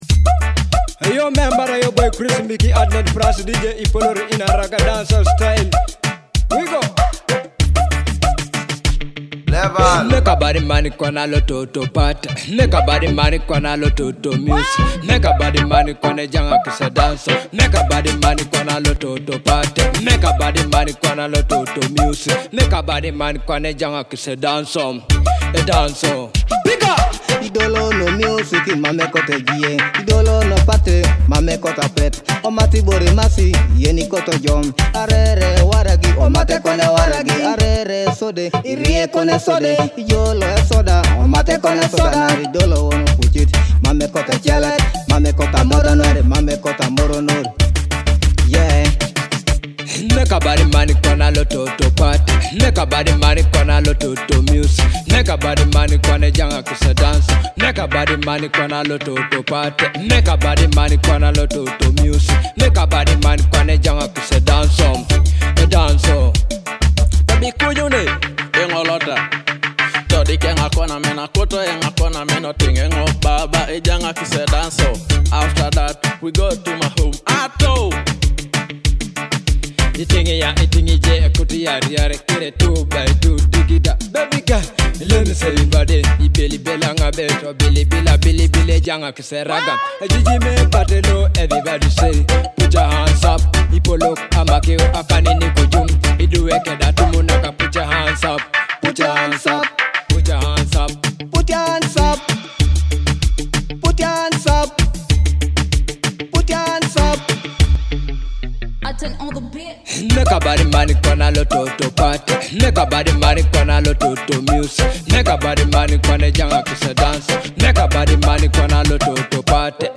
a lively Afrobeat party track